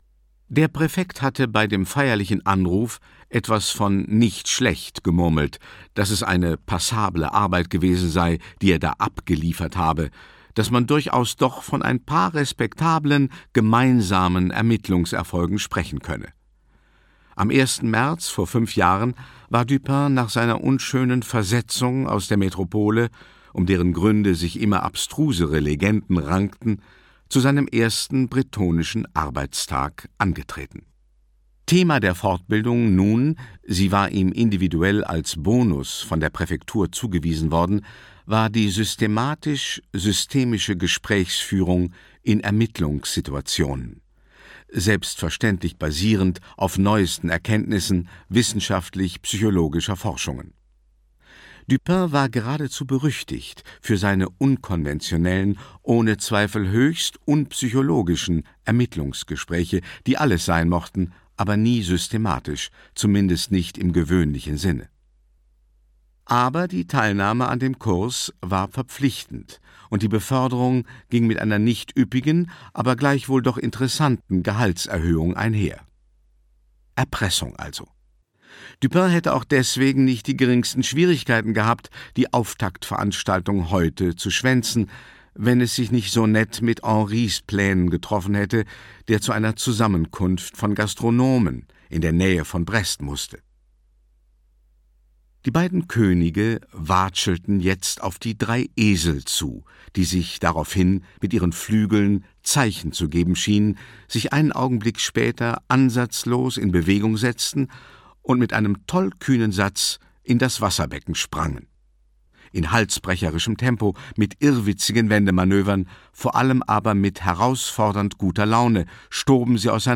Bretonischer Stolz - Jean-Luc Bannalec - Hörbuch